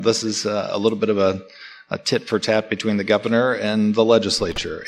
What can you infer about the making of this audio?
at the Kalamazoo City Commission meeting May 2, 2022